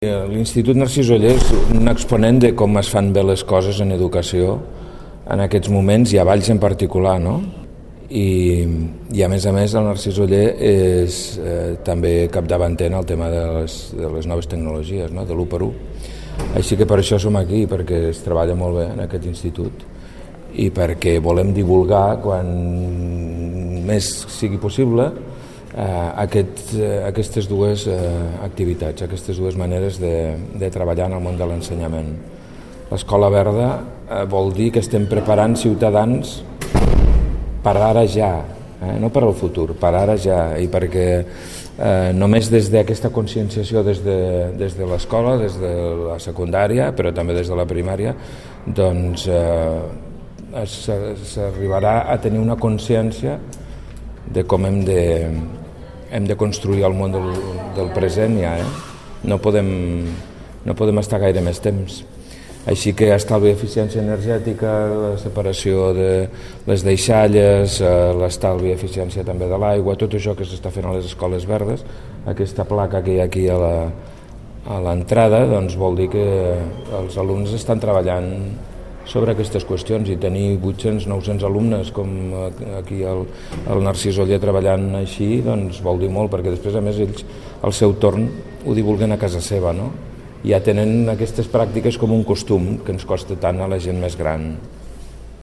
Tall de veu del delegat del Govern